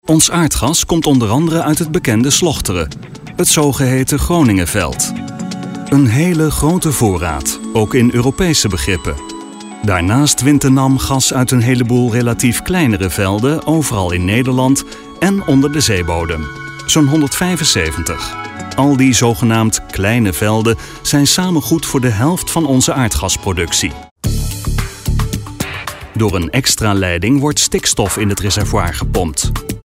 mit eigenem Studio
Sprechprobe: Industrie (Muttersprache):
all round native dutch male voice over talent with own studio